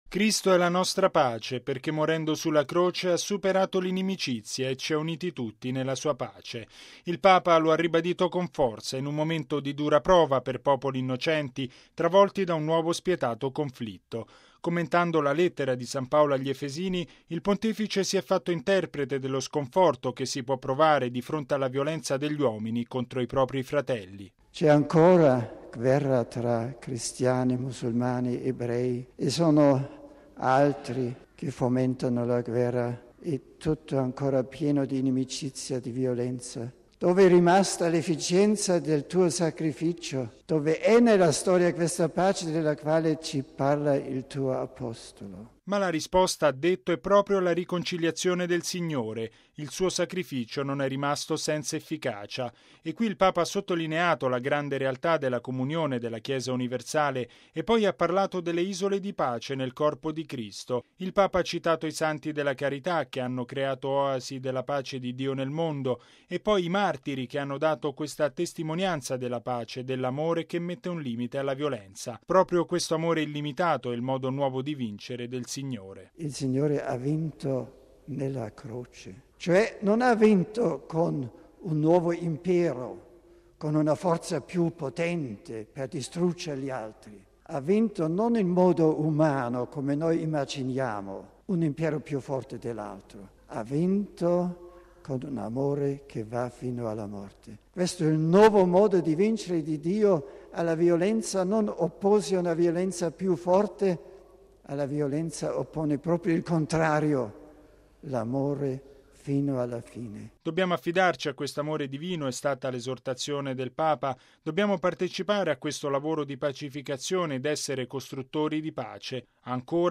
Dopo l’Angelus di domenica, recitato a Les Combes, il Papa nel pomeriggio si è recato nella parrocchia valdostana di Rhemes Saint Georges, dove si è raccolto in preghiera. Il servizio